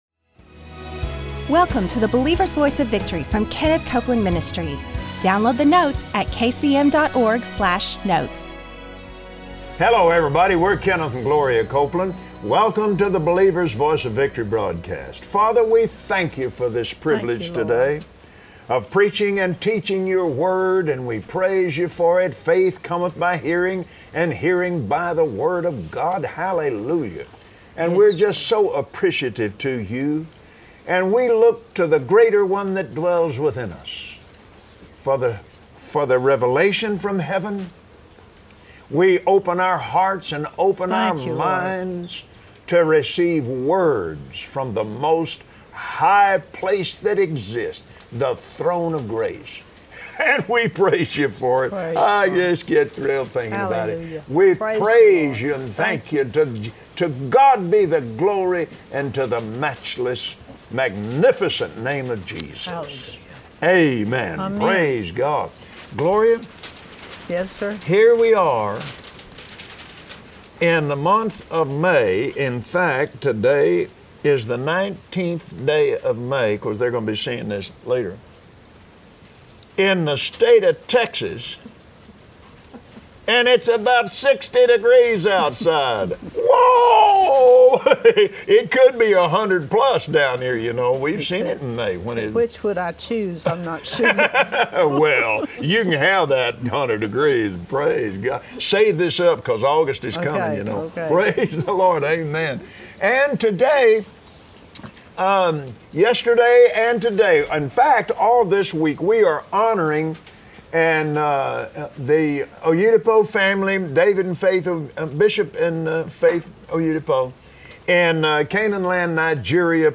Today, on the Believer’s Voice of Victory, Kenneth and Gloria Copeland teach you how to live protected in God’s love - fearing not, but believing only what the Word says.